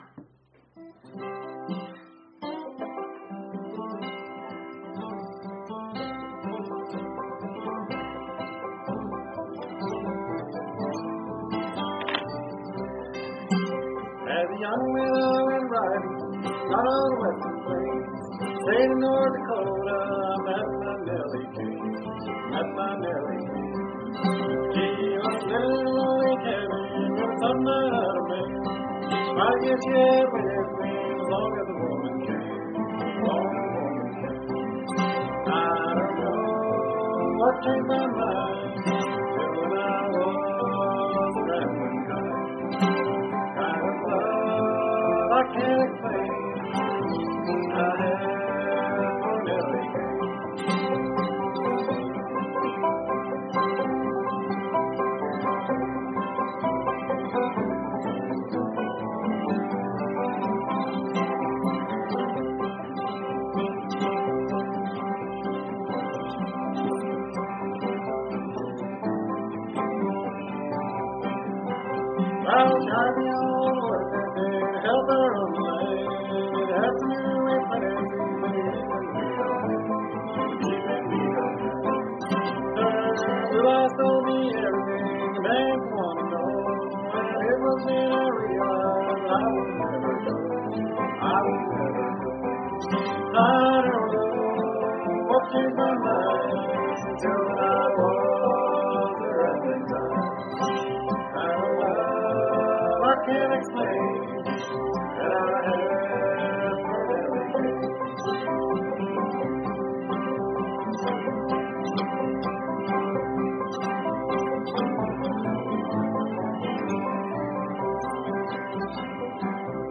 Bluegrass Jam-Sessions in Dresden – Mach mit!
Spiele Bluegrass in entspannter Atmosphäre! Unsere Jam-Sessions in Dresden bieten die perfekte Gelegenheit, dein Können auf Banjo, Gitarre oder Geige zu zeigen und dich mit anderen Musikern zu vernetzen.